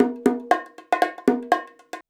119BONG13.wav